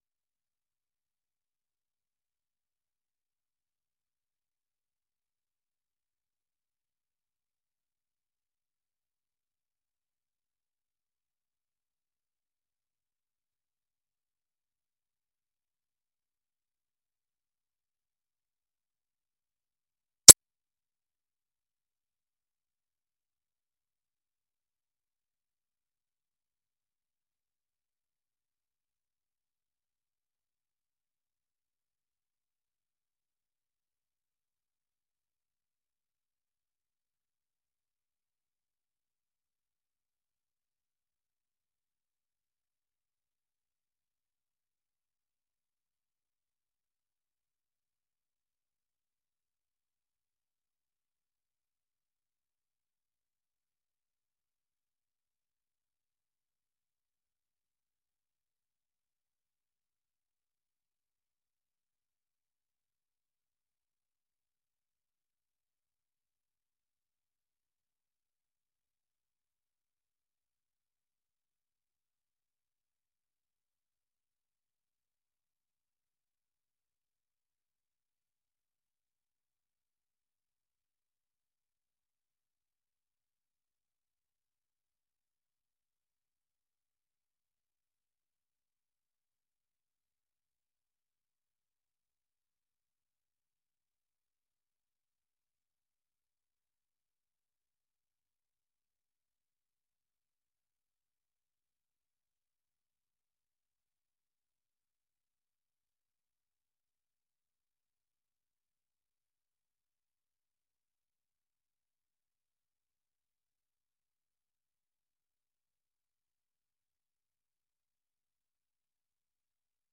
برنامه خبری آشنا